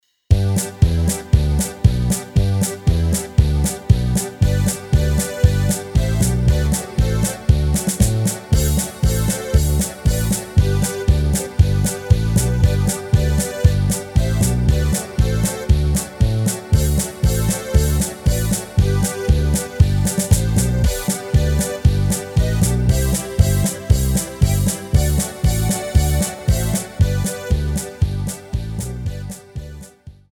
Rubrika: Národní, lidové, dechovka
- polka
2 - takty - Gdur